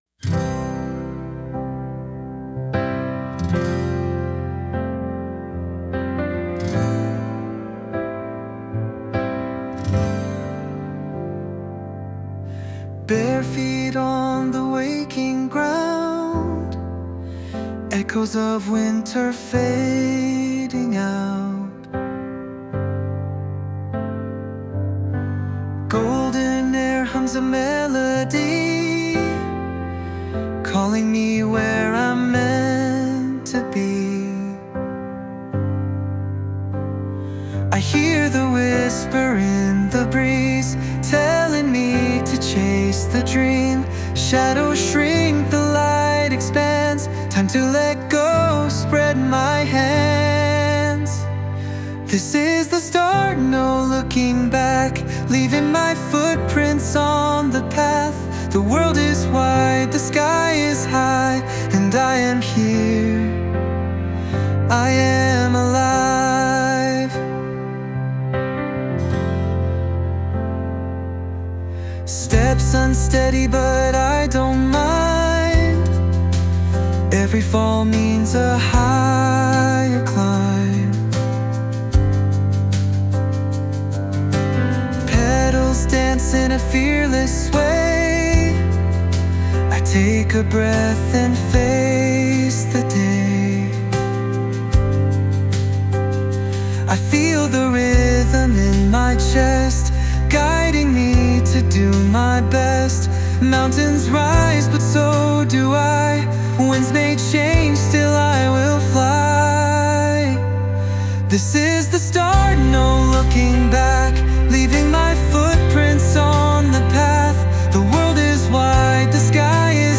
洋楽男性ボーカル著作権フリーBGM ボーカル
著作権フリーオリジナルBGMです。
男性ボーカル（洋楽・英語）曲です。
春の新しい出発をテーマに優しい声・メロディーで歌った曲です。